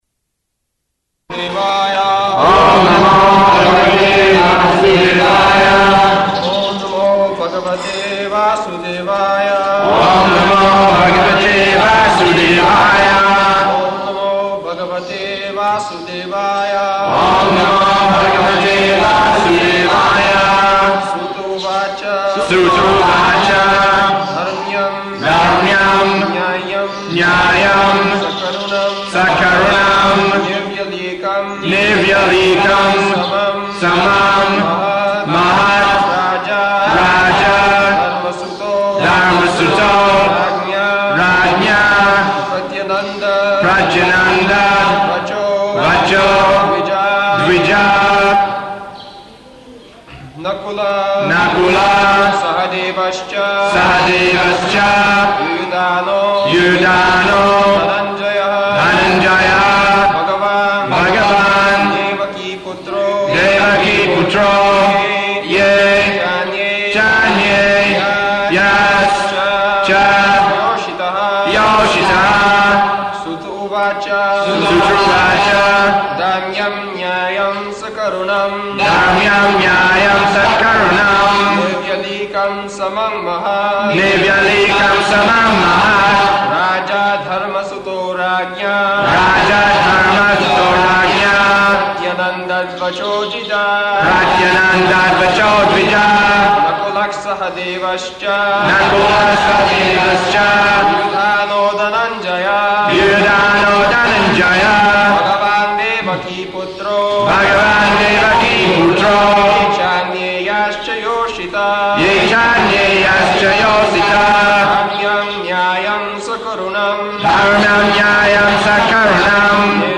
-- Type: Srimad-Bhagavatam Dated: October 7th 1976 Location: Vṛndāvana Audio file
[devotees repeat] [chants verse]